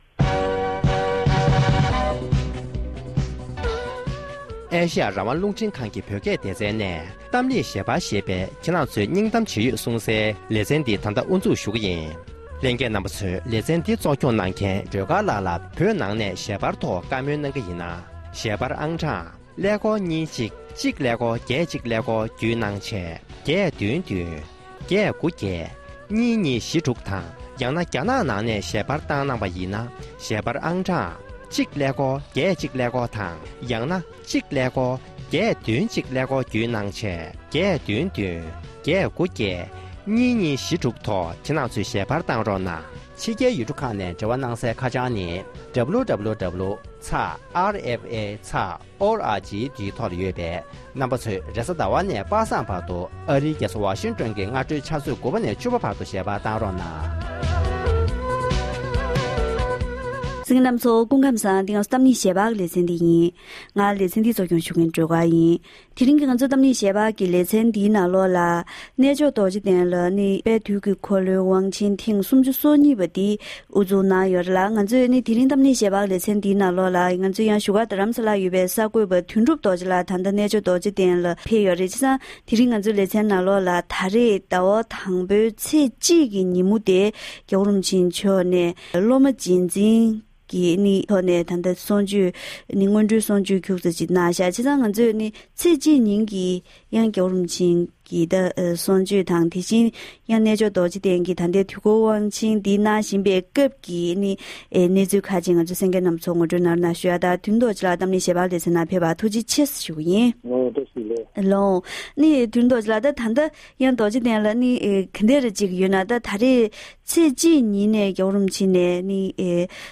༸གོང་ས་མཆོག་ནས་དཔལ་དུས་ཀྱི་འཁོར་ལོའི་སློབ་མ་རྗེས་འཛིན་སྐབས་ཀྱི་བཀའ་སློབ།